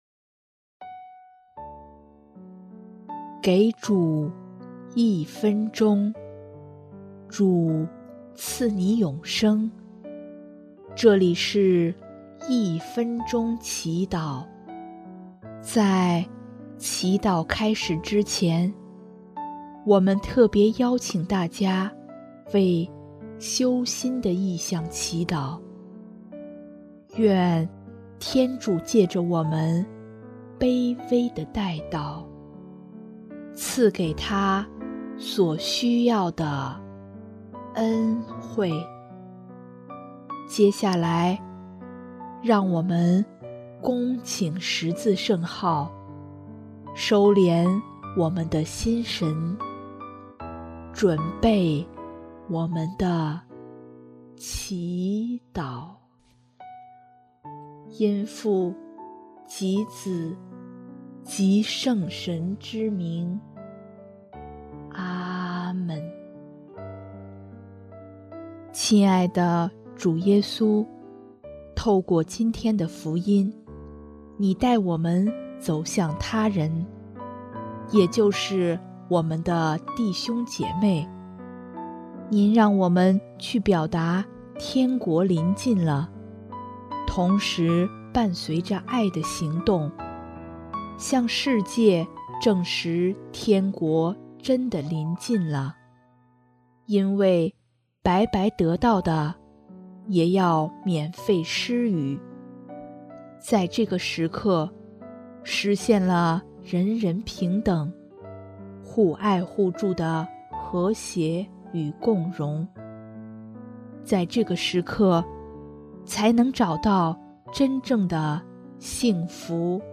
【一分钟祈祷】|7月11日 用善意去理解他人